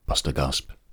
It also de-clicks the beginnings (see attached)